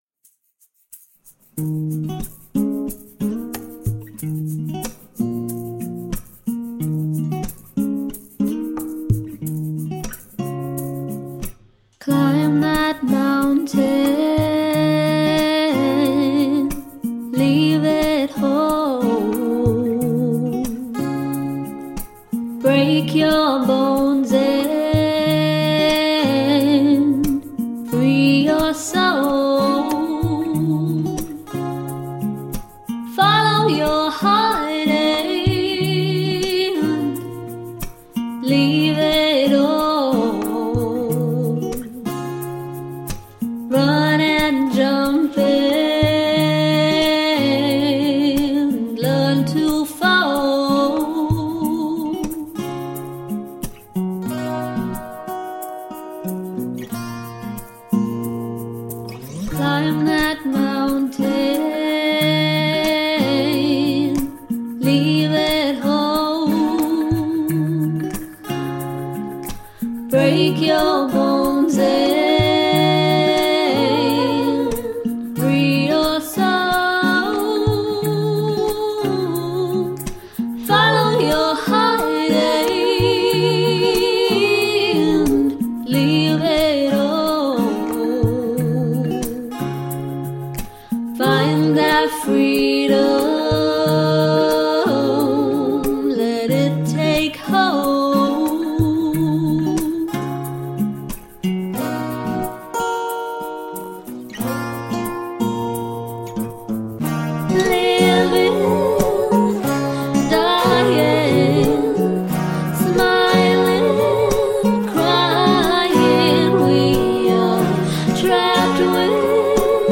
Acoustic folk duo